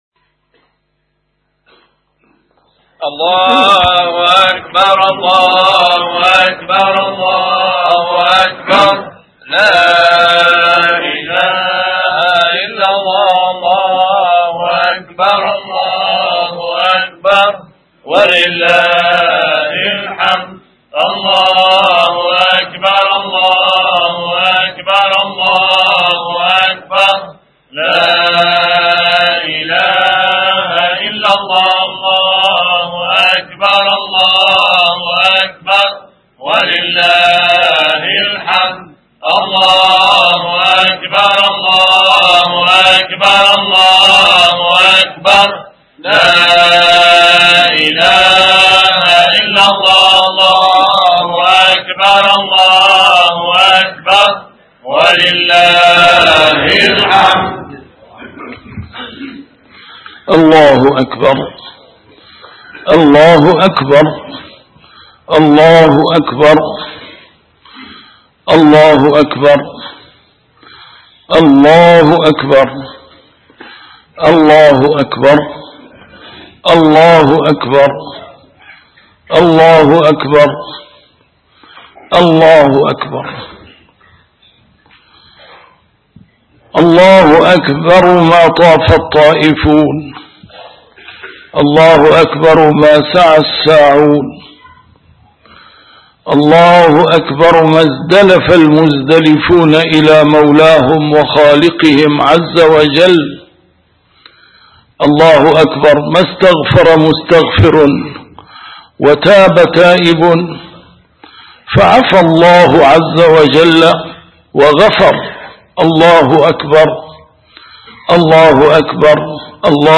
A MARTYR SCHOLAR: IMAM MUHAMMAD SAEED RAMADAN AL-BOUTI - الخطب - خطبة عيد الأضحى المبارك